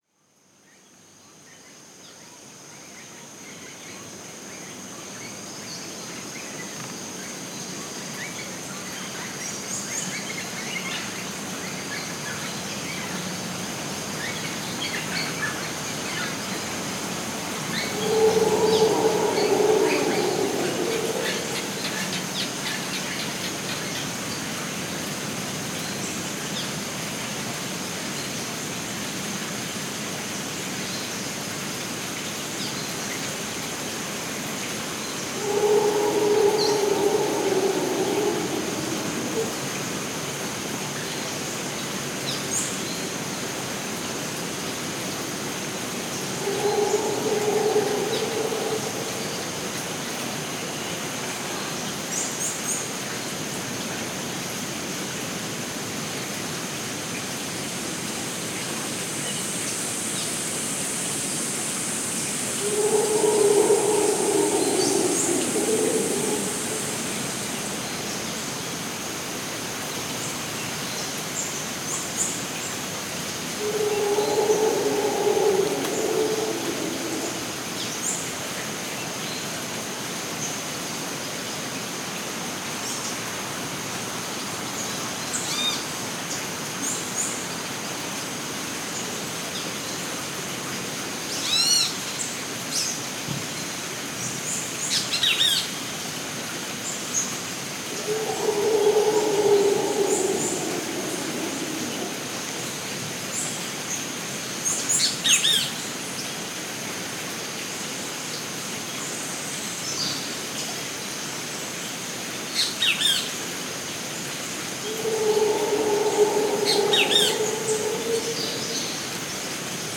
Costa Rica Rainforest
Category 🌿 Nature
ambient biosphere bird birds birdsong bugs canopy Central-America sound effect free sound royalty free Nature